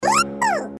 • Качество: 320, Stereo
забавные
без слов
Забавный звук для уведомлений и сообщений